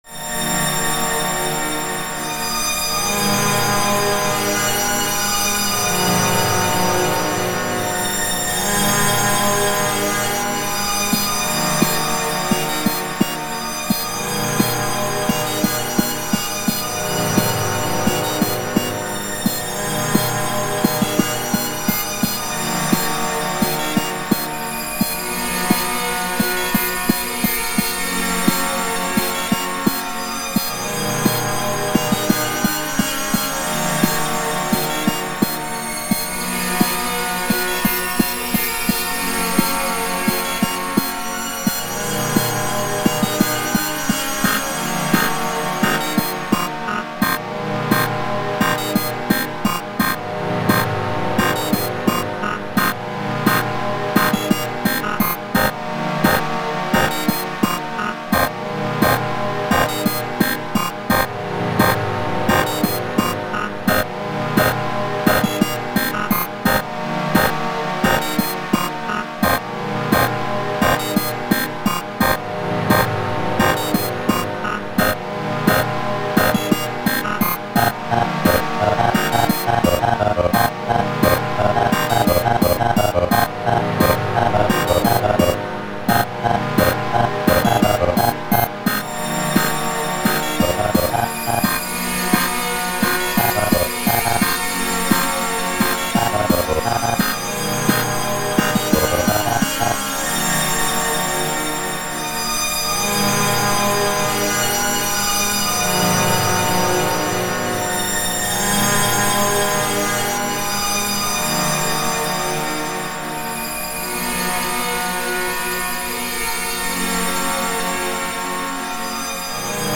All the samples in this song were generated within Milkytracker itself. Less surprising for the robot noises, but also the "strings" too.
I feel like I am getting better at making music; things are starting to feel more layered and like the kind of music I actually want to put out.